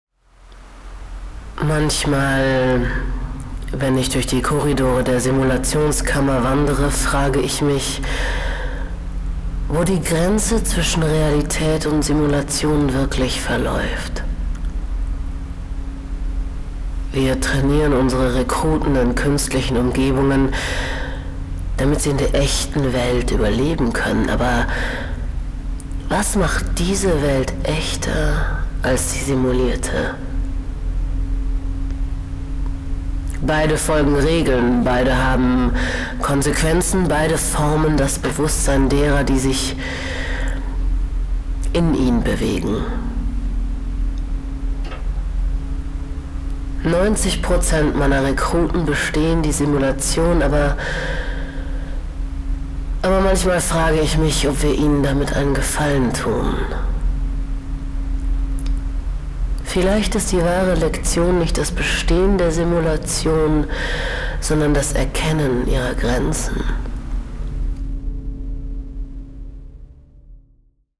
Begleitet von binauralem Sounddesign erleben Sie ein akustisches Abenteuer, das Sie mitten in die Inszenierung zieht.